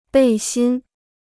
背心 (bèi xīn): sleeveless garment (vest, waistcoat, singlet, tank top etc).